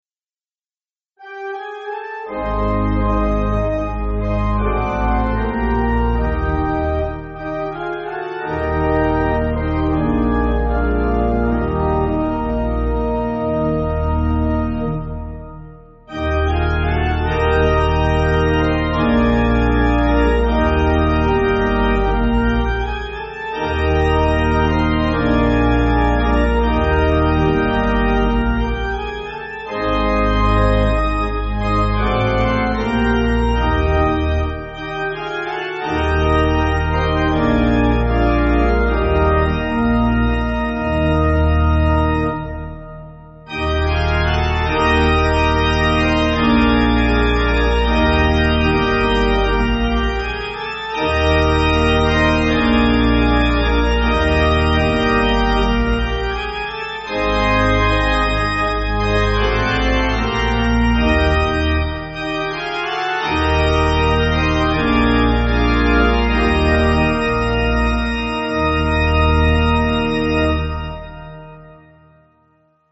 (CM)   2/Eb